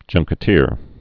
(jŭngkĭ-tēr)